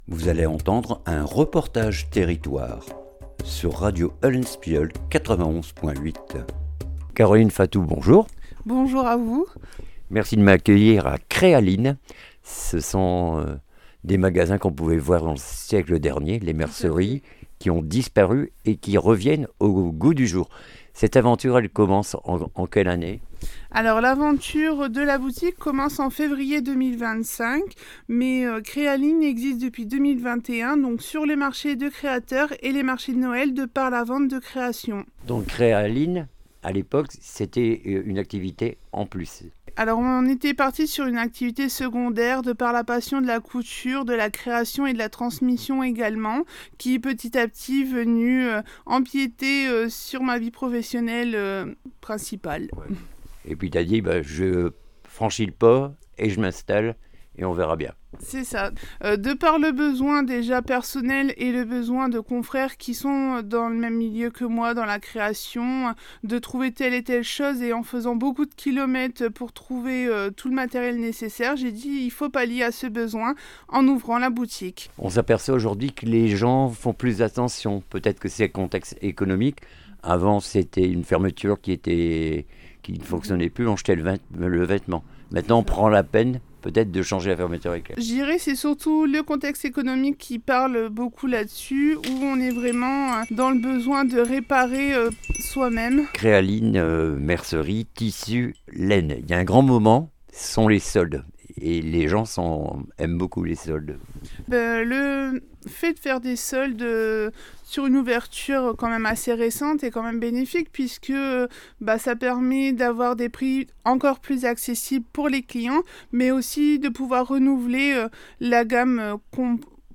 REPORTAGE TERRITOIRE CREA LINE WORMHOUT